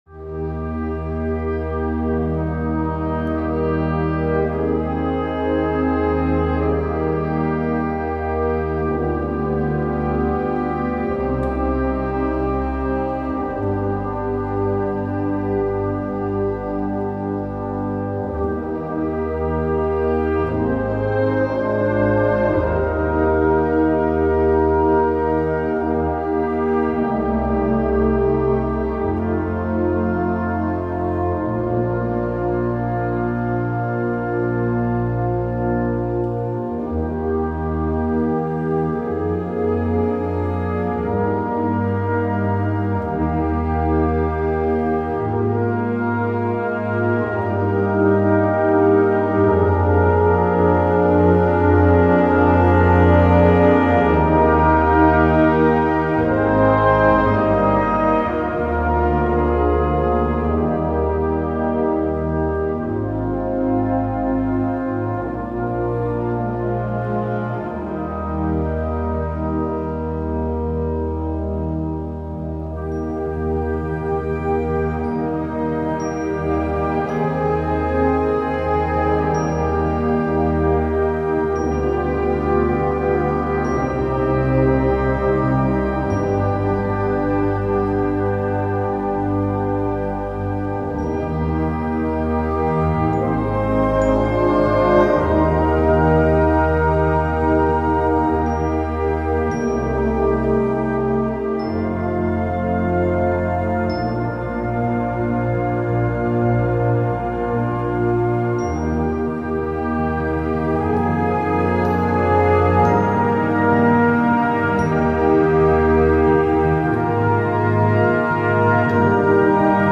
Gattung: Choral für Blasorchester
Besetzung: Blasorchester